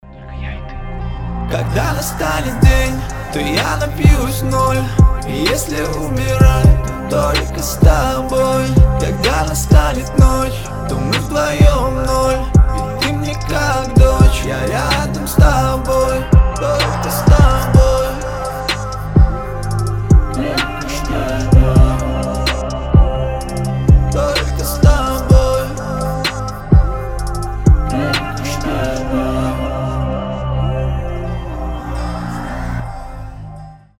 • Качество: 320, Stereo
лирика
Хип-хоп
спокойные